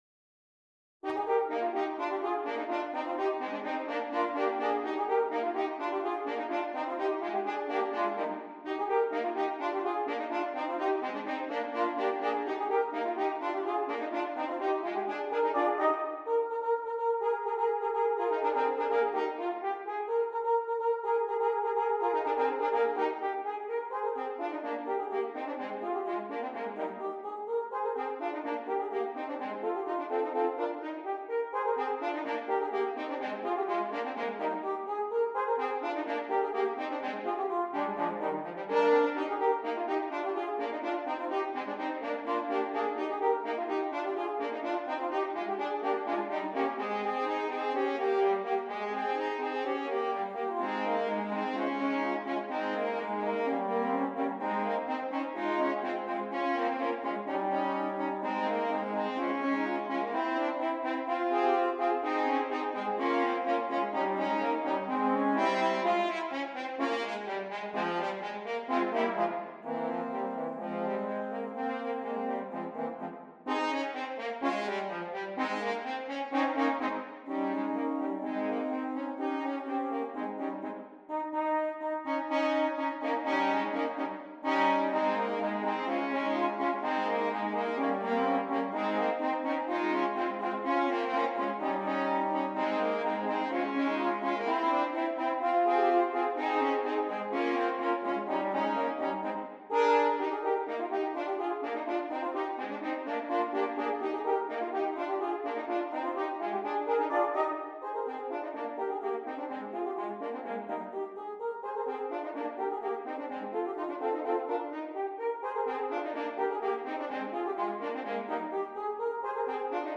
3 F Horns
light-hearted